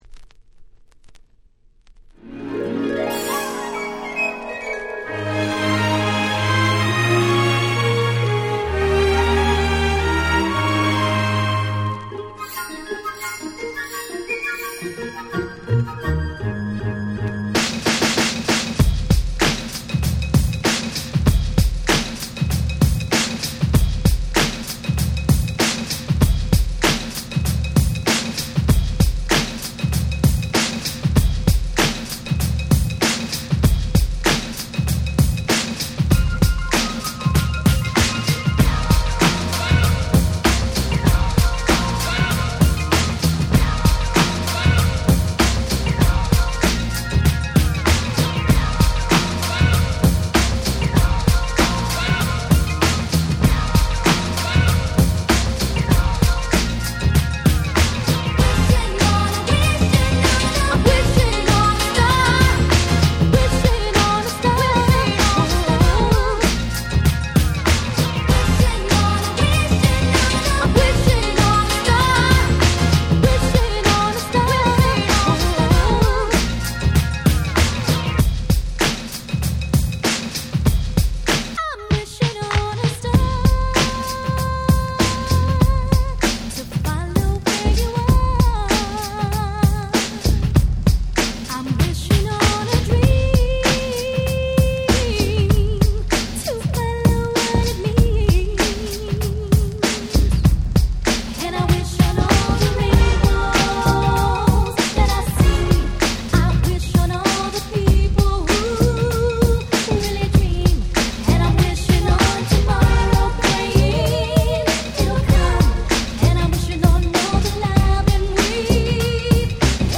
90's R&B